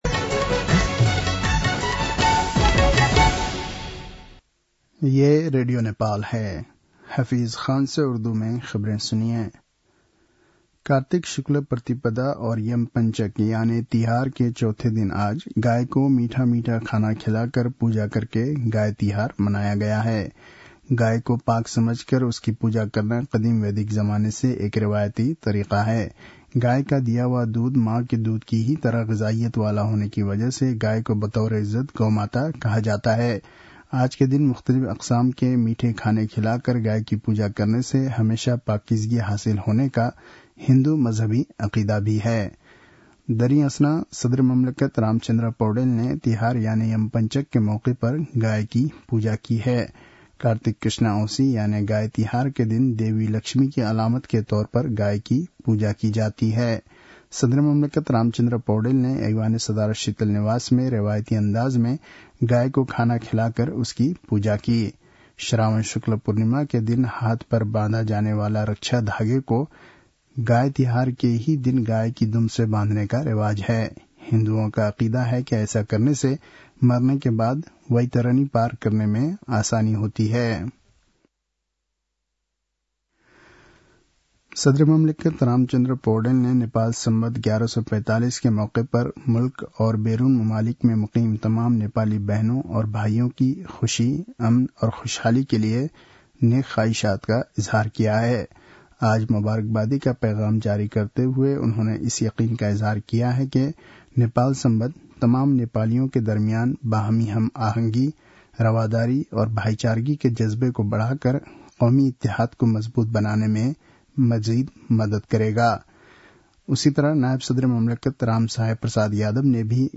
उर्दु भाषामा समाचार : १८ कार्तिक , २०८१
URDU-NEWS-07-17.mp3